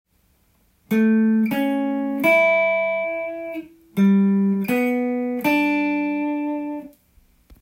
ギターソロでかなり使える【トライアド】
A7を例にして解説です。